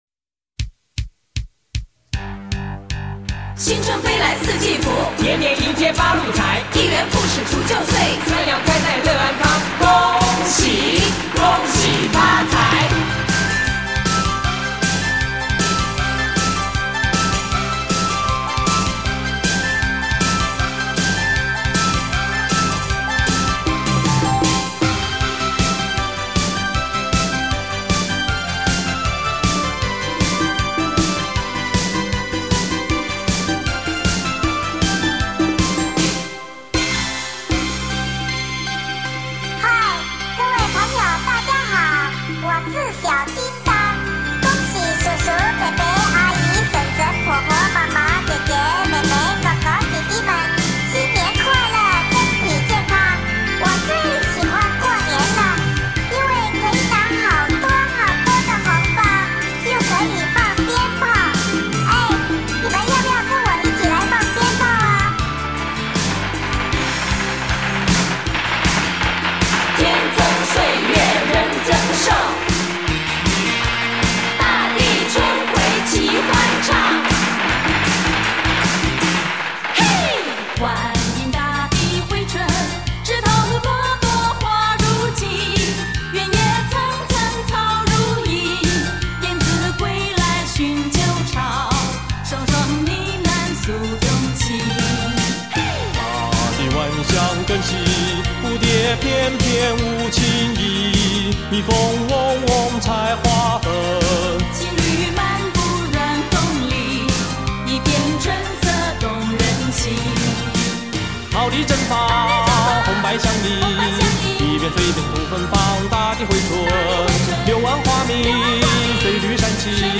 连续热唱NO STOP  两首贺年组曲
组曲